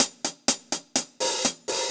Index of /musicradar/ultimate-hihat-samples/125bpm
UHH_AcoustiHatB_125-04.wav